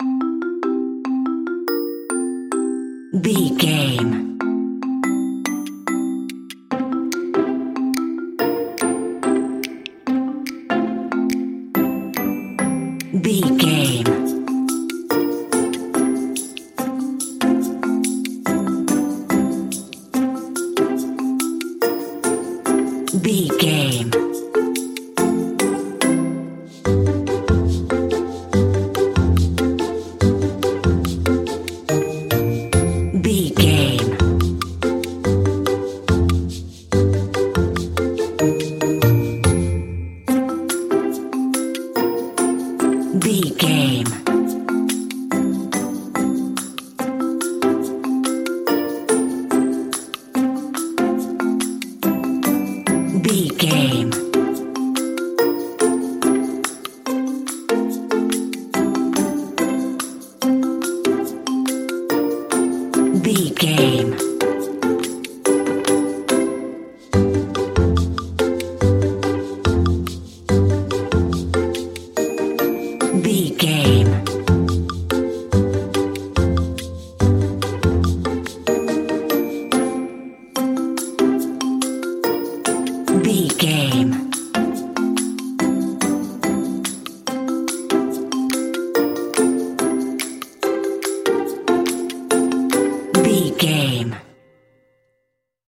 Mixolydian
clapping
playful
orchestral
strings
pizzicato
marimba
xylophone
violin
double bass
harp